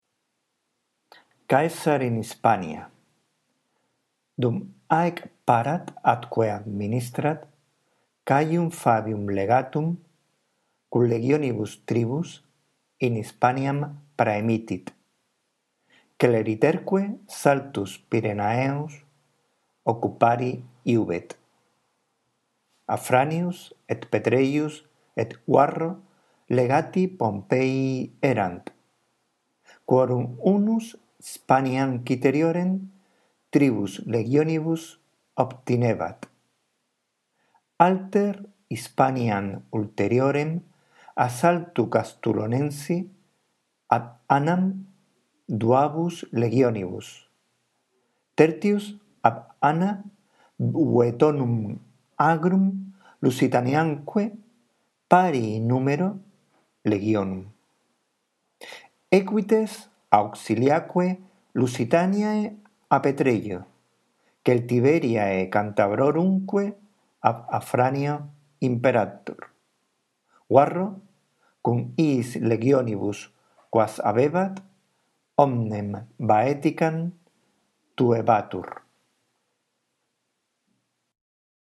Tienes una lectura justo debajo del texto por si la necesitas.
Después de escuchar con atención nuestra lectura del texto latino, lee tú despacio procurando pronunciar correctamente cada palabra y entonando cada una de las oraciones.